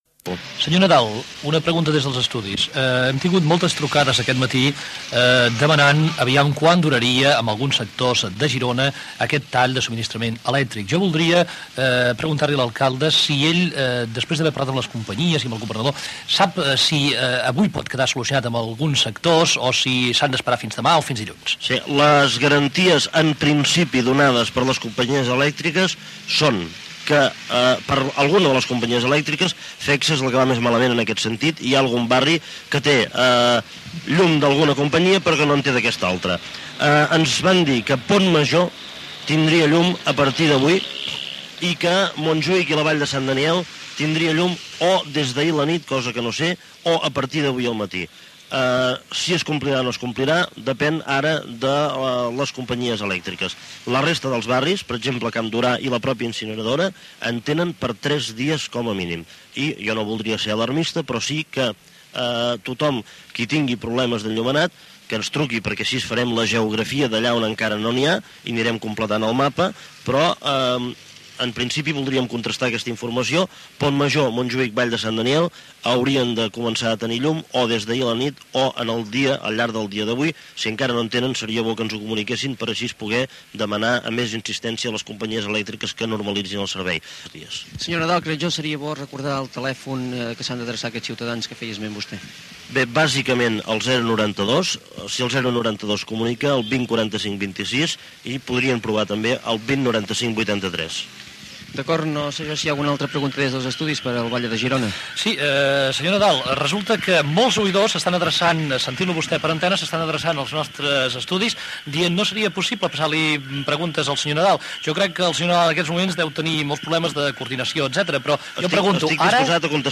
Entrevista a l'alcalde de Girona Joaquím Nadal sobre la represa del subministrament de llum. Pregunta d'una oïdora a l'alcalde sobre el tema